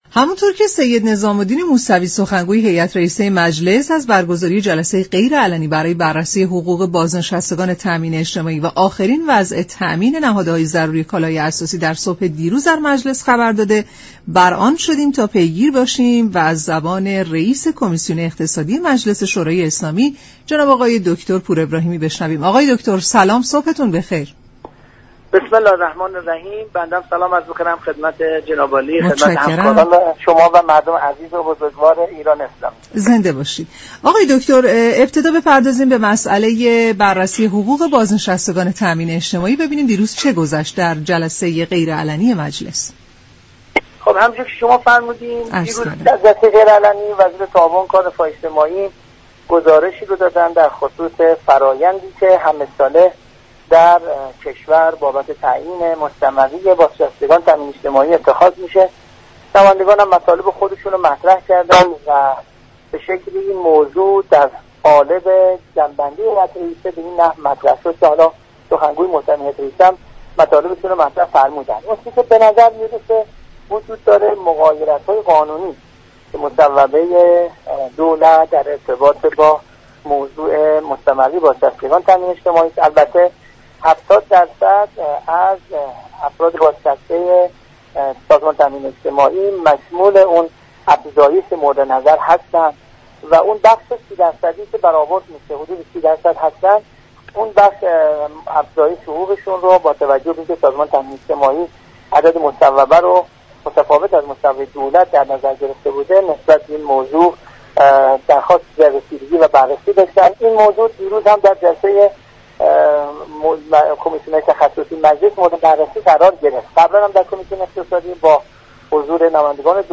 به گزارش شبكه رادیویی ایران، «محمدرضا پور ابراهیمی» رییس كمیسیون اقتصادی مجلس، در برنامه «سلام صبح بخیر» به جزئیات جلسه غیرعلنی مجلس در مورد افزایش حقوق بازنشستگان سازمان تامین اجتماعی اشاره كرد و گفت: روز گذشته در جلسه غیر علنی مجلس كه با حضور وزیر تعاون، كار و رفاه اجتماعی برگزار شد موضوع افزایش حقوق بازنشستگان مورد بررسی قرار گرفت.